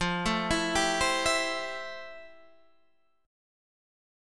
FM9 Chord
Listen to FM9 strummed